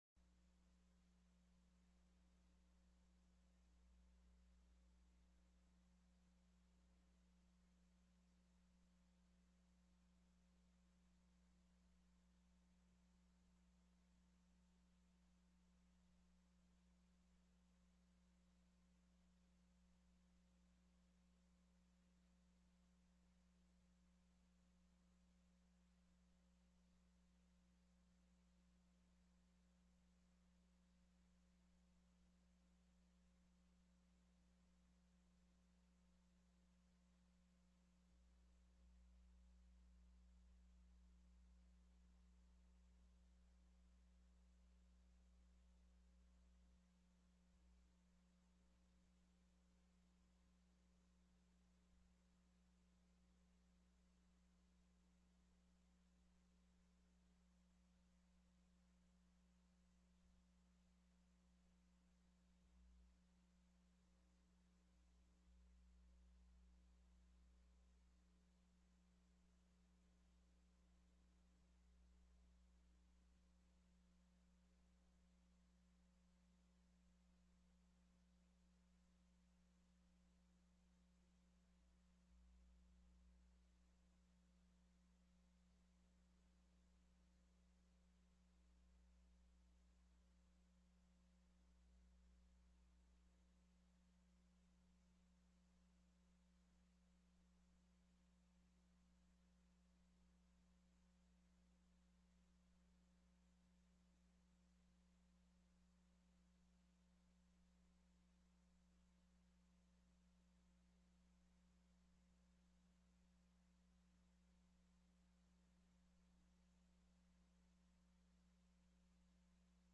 05/15/2019 09:00 AM House FINANCE
The audio recordings are captured by our records offices as the official record of the meeting and will have more accurate timestamps.
+ Bills Previously Heard/Scheduled TELECONFERENCED